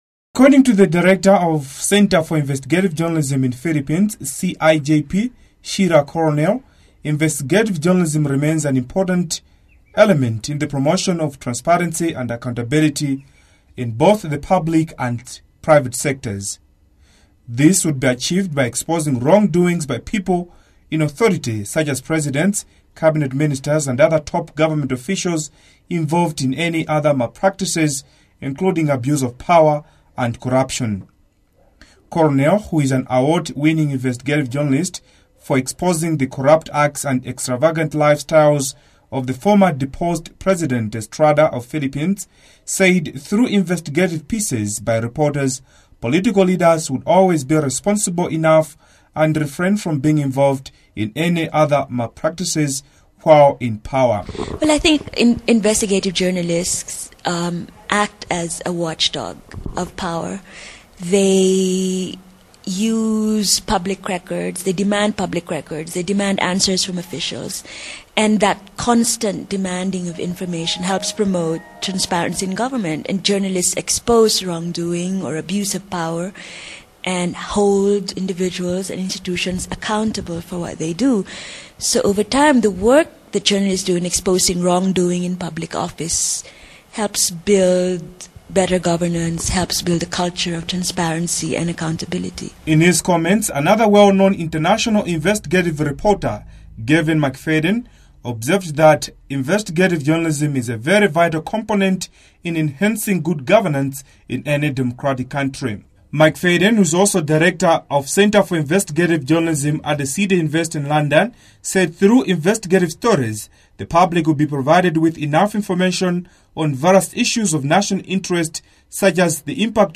Radioworkshop
After one week of intensive working on these topics, one thing is sure: both produced radio programmes are ear-catchers: